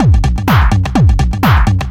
DS 126-BPM A04.wav